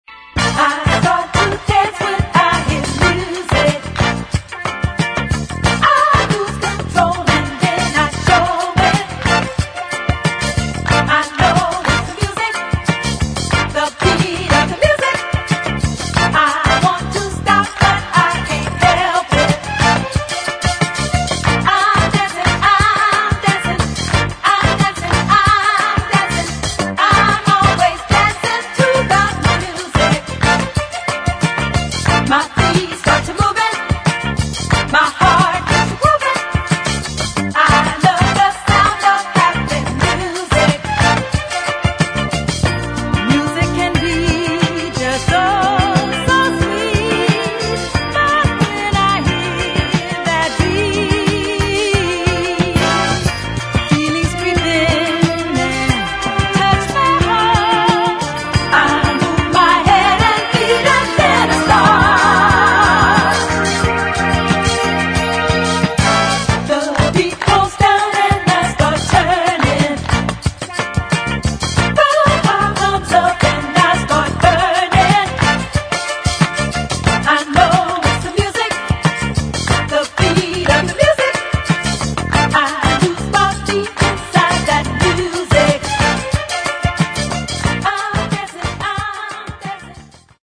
[ DISCO / FUNK ]